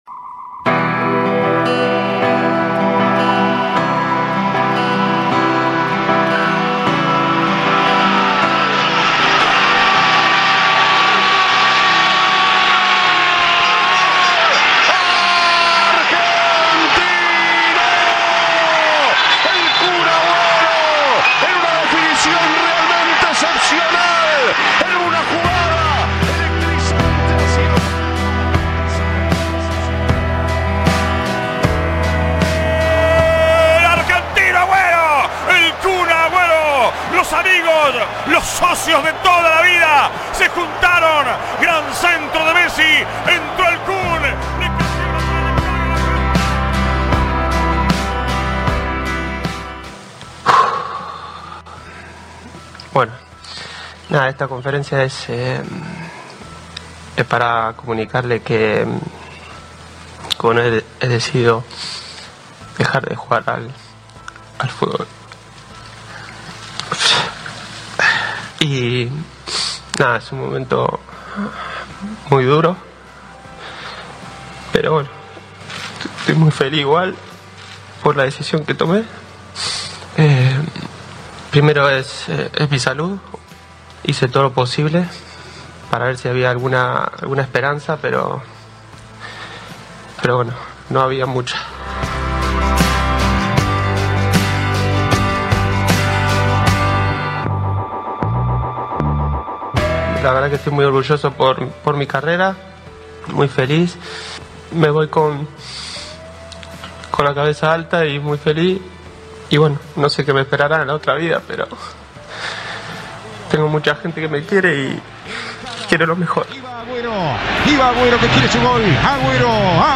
El futbolista se despidió de la actividad profesional producto del problema cardíaco que lo aqueja. Un compilado de audios repasa momentos emotivos de su carrera.